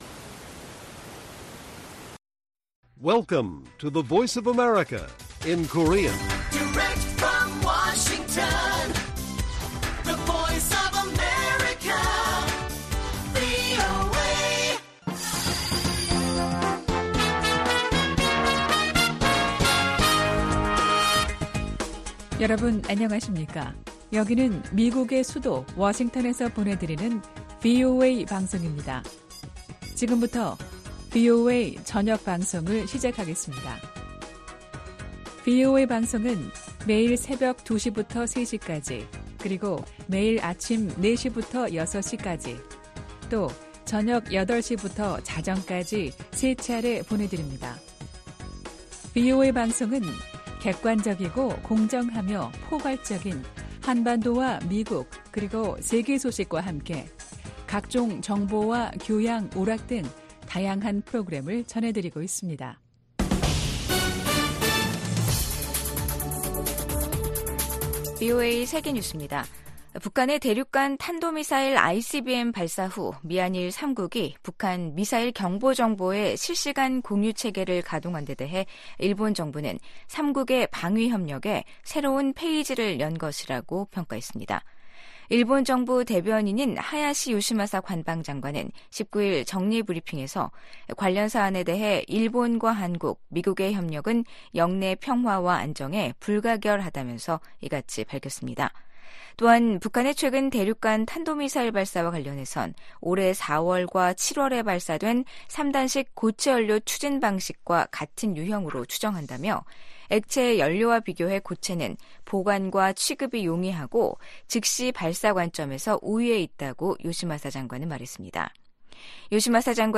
VOA 한국어 간판 뉴스 프로그램 '뉴스 투데이', 2023년 12월 19 일 1부 방송입니다. 유엔 안보리가 북한의 대륙간탄도미사일(ICBM) 발사에 대응한 긴급 공개회의를 개최합니다. 미 국무부는 중국에 북한의 개발 핵 야욕을 억제하도록 건설적 역할을 촉구했습니다. 북한은 어제(18일) 고체연료 기반의 대륙간탄도미사일 ‘화성-18형’ 발사 훈련을 실시했다며 미국 본토에 대한 핵 위협을 노골화했습니다.